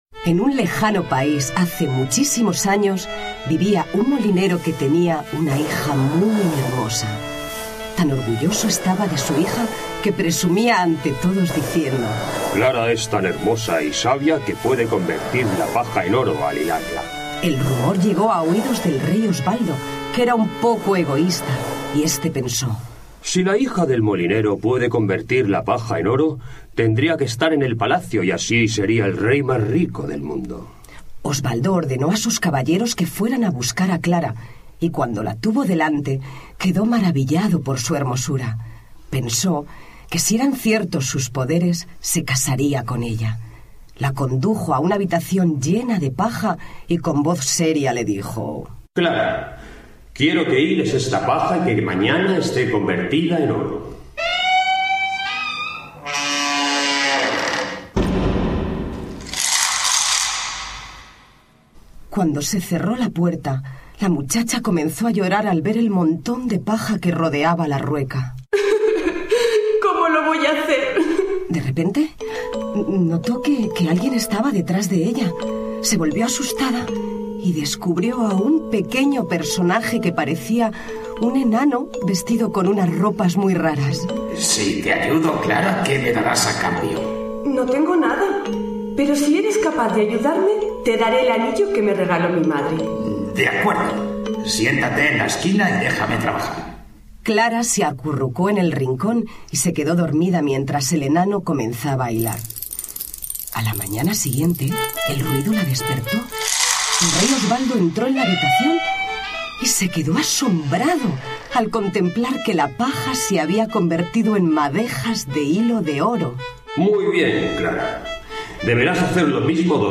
cuento
Cuentos infantiles